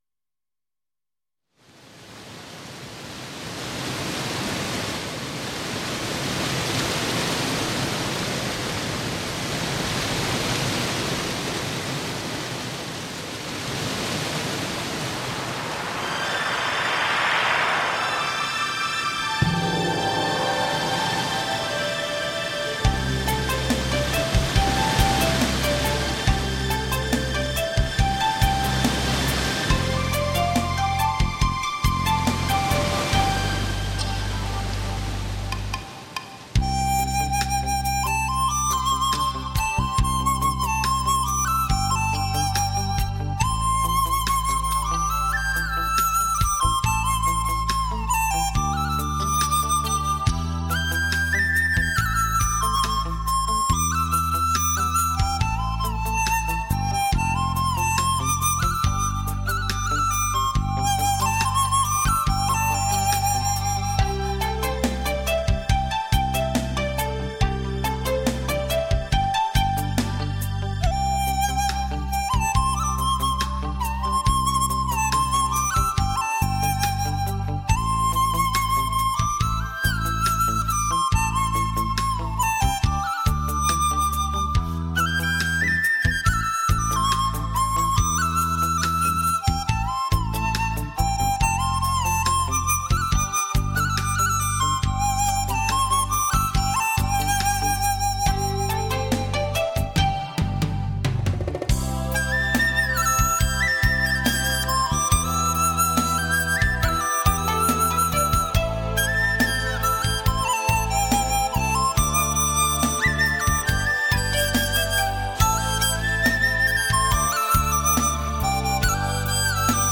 休闲音乐系列
竹笛